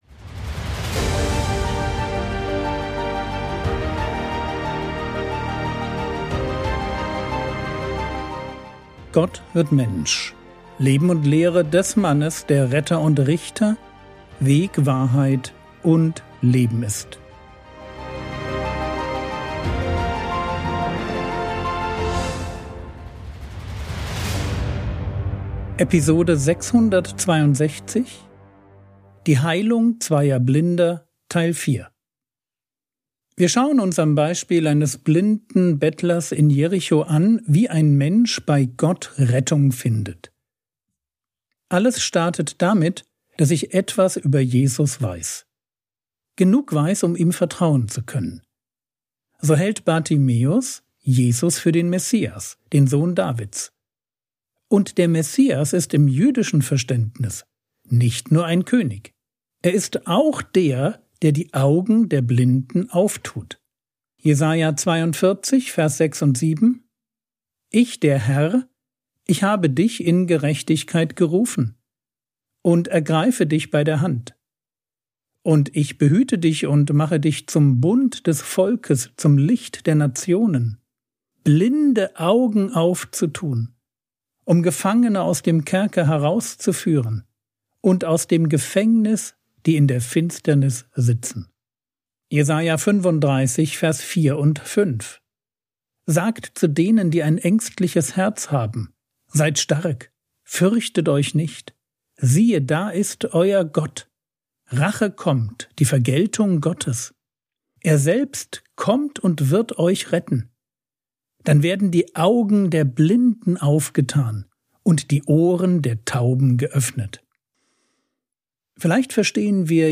Episode 662 | Jesu Leben und Lehre ~ Frogwords Mini-Predigt Podcast